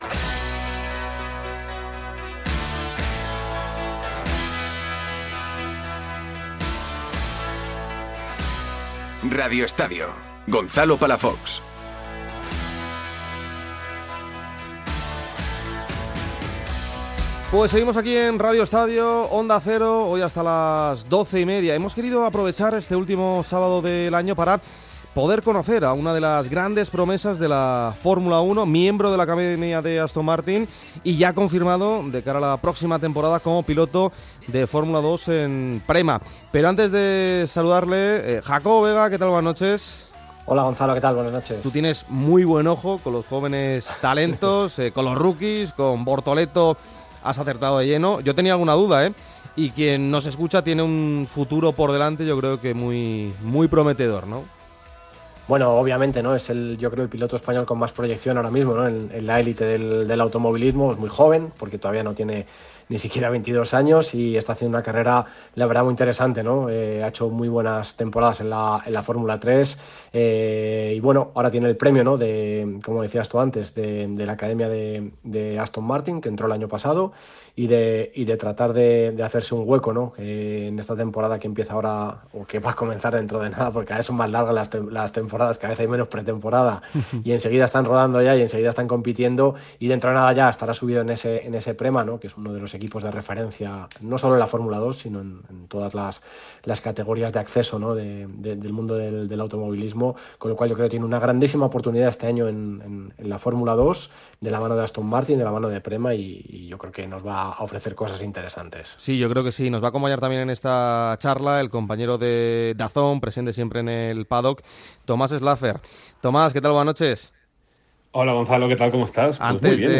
Todo el deporte en directo. El eje que sujeta al programa será el fútbol y LaLiga, pero no perdemos detalle de todos los deportes del país, y de aquellos eventos en el que participen nuestros deportistas por el mundo.